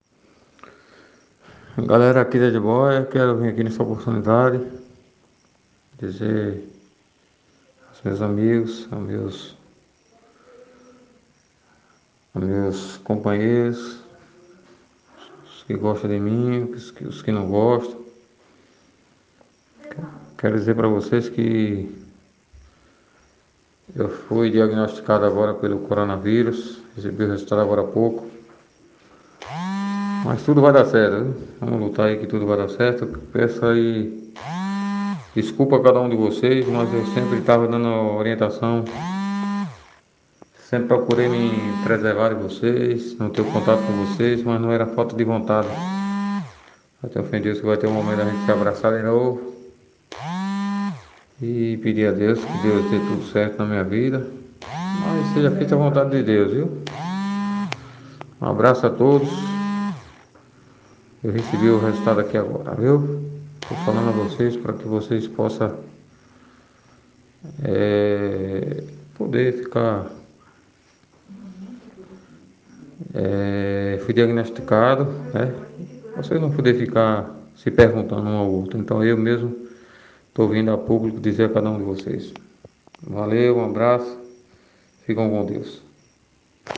A Prefeitura Municipal de Retirolândia, através da Secretaria de Saúde, divulgou neste sábado, 23, mais um Boletim Epidemiológico, que registra mais três novos casos positivos, um deles, o vereador Sandro da Vargem que chegou a gravar um áudio para comunicar o resultado a população retirolandense direcionando o pessoa do povoado Gibóia – Ouça